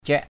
/ciaʔ/ (đg.) cắt, xắt = couper, trancher. ciak pakaw c`K pk| xắt thuốc = hacher le tabac. mâk taow ciak taduk mK _t<| c`K td~K lấy dao xắt rau =...
ciak.mp3